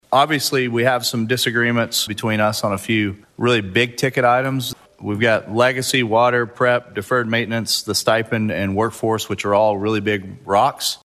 CLICK HERE to listen to commentary from Senate President Pro Tem Greg Treat.